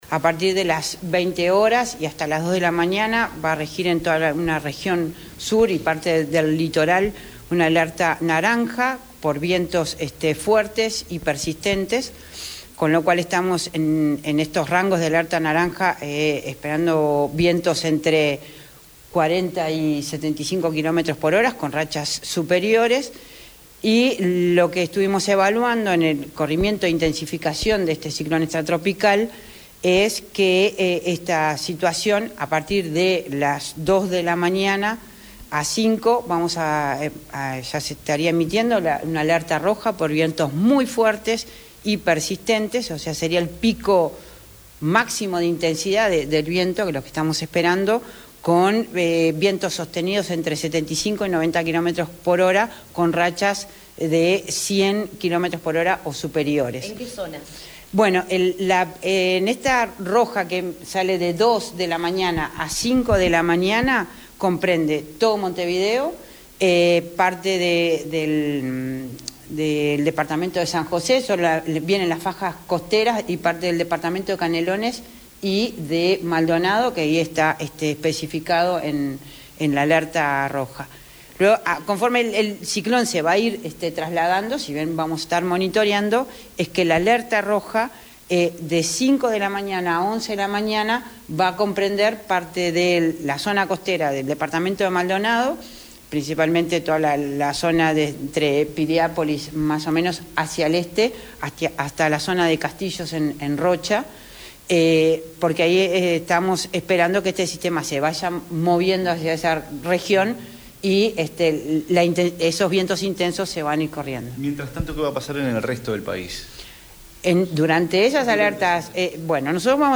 El Instituto Nacional de Meteorología emitió una alerta color roja por vientos fuertes y muy fuertes desde la hora 02:00 y hasta las 05:00 en Montevideo y zonas de la costa de San José, Canelones y Maldonado; y desde la hora 05:00 a las 11:00 en la costa de Maldonado y Rocha. La presidenta del Inumet, Madeleine Renom, detalló la advertencia.